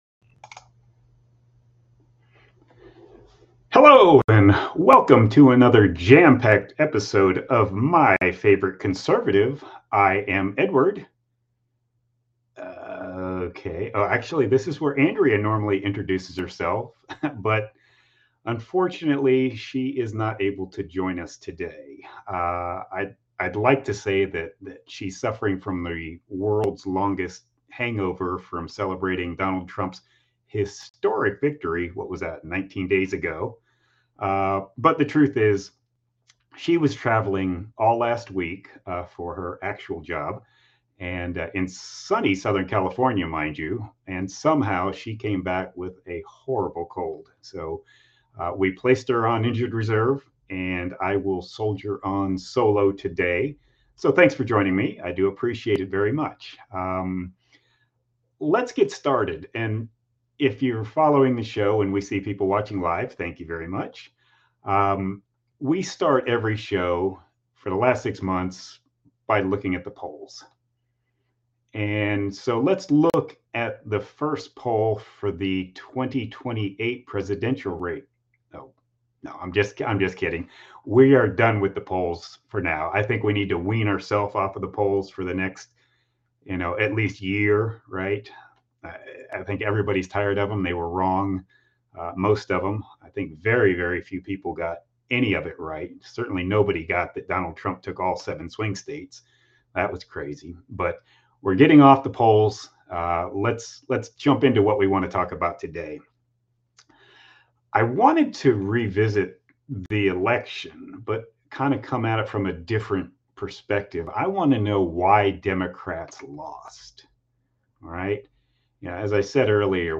Warning: There may be some squabbling. And swearing.